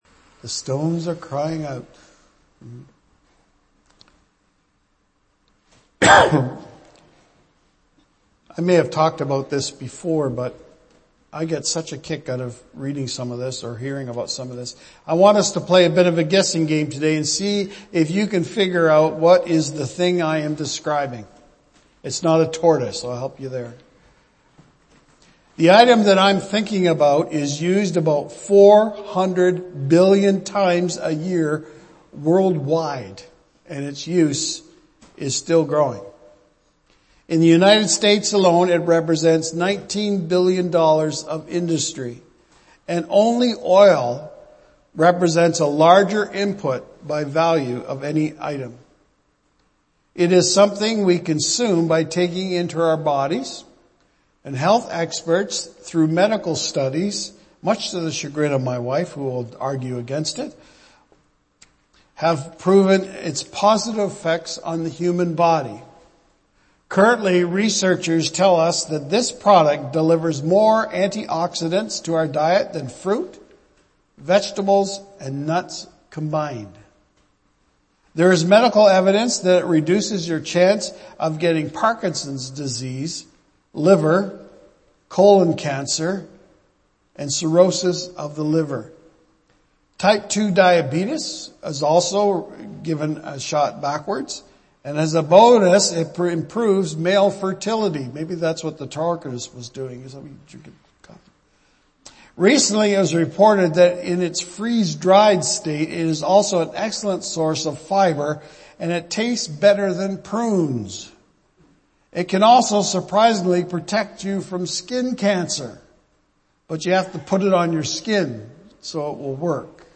Archived Sermons
Palm Sunday